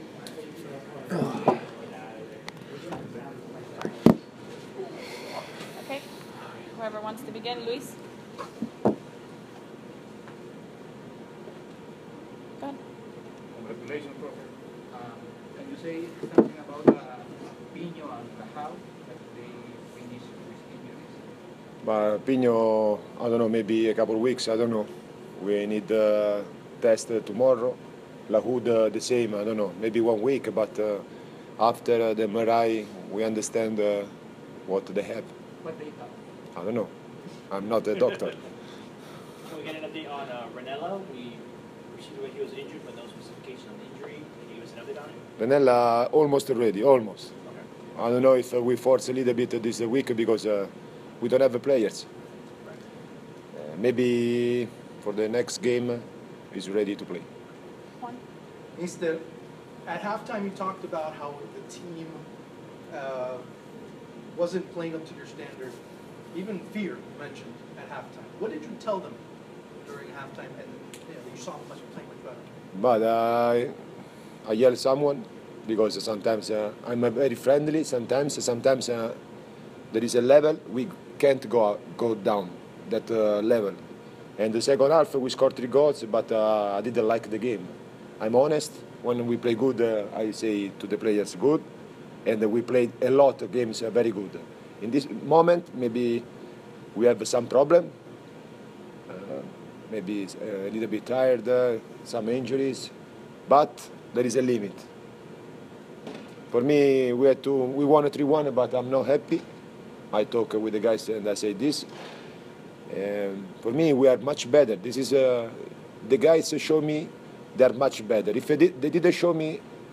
We had a chance to speak with Nesta at the post game conference. Both players will be assessed in the days to come but seem doubtful for the upcoming clash with North Carolina FC.
We have the gaffer’s audio for you.